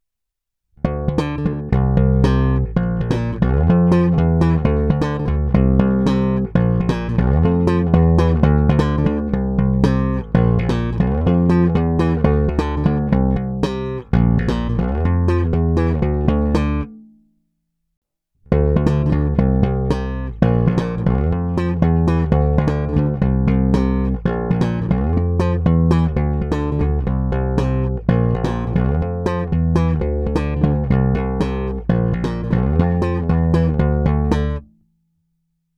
Zvuk je typický Precision.
Není-li uvedeno jinak, následující nahrávky jsou vyvedeny rovnou do zvukové karty, vždy s plně otevřenou tónovou clonou, a kromě normalizace ponechány bez úprav.